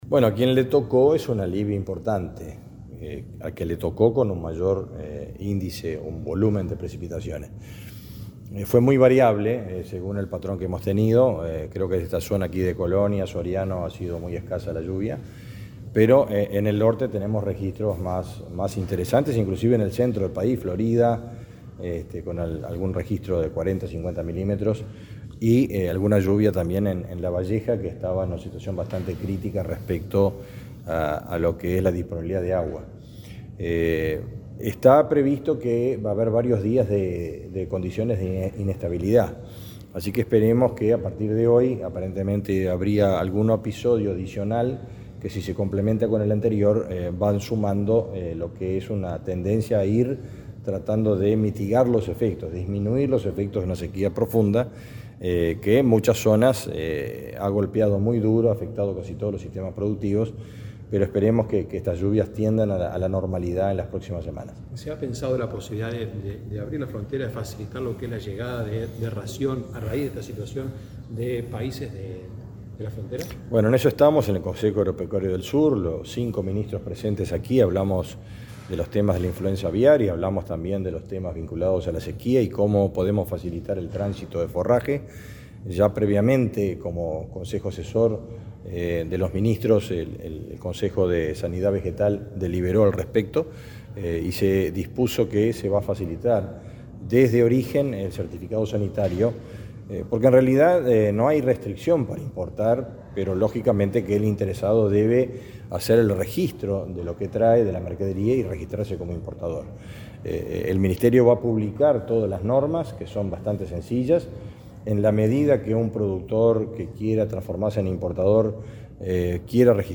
Declaraciones del ministro de Ganadería, Fernando Mattos
Luego, dialogó con la prensa sobre otras temáticas.